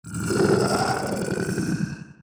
evil-deer-v3.ogg